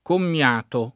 kommL#to] (ant. comiato [komL#to]) s. m. — con doppia -m- la forma originaria, dal lat. commeatus — scempiam. antico con diversa fortuna in comiato, dove non è sopravvissuto, e in accomiatare, dove si può dire ancóra prevalente